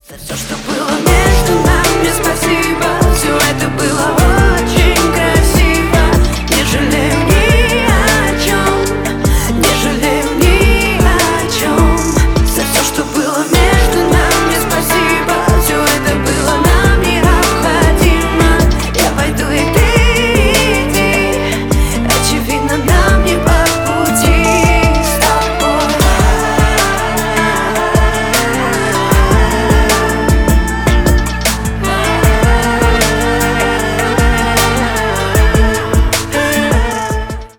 бесплатный рингтон в виде самого яркого фрагмента из песни
Поп Музыка
грустные